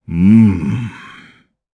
Bernheim-Vox_Think_jp.wav